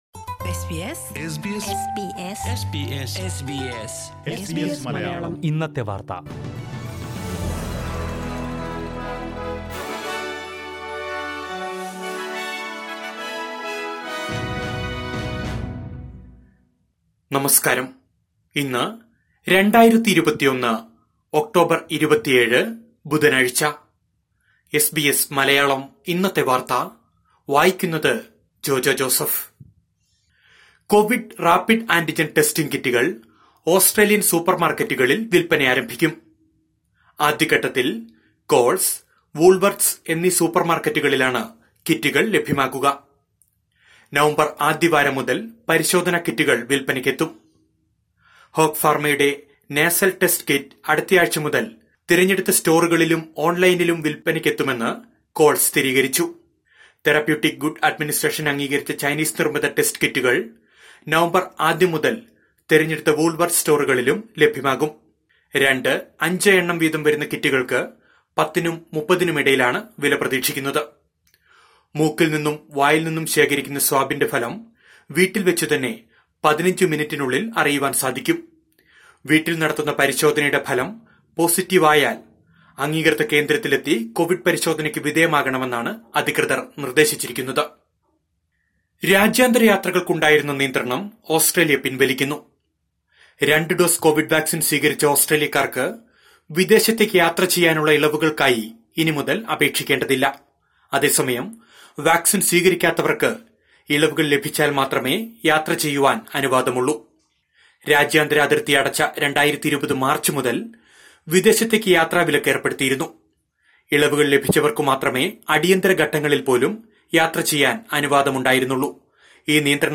2021 ഒക്ടോബർ 27ലെ ഓസ്ട്രേലിയയിലെ ഏറ്റവും പ്രധാന വാർത്തകൾ കേൾക്കാം...